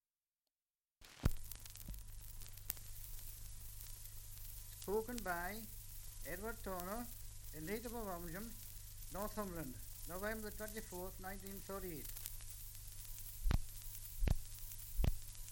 Dialect recording in Ovingham, Northumberland
78 r.p.m., cellulose nitrate on aluminium
English Language - Dialects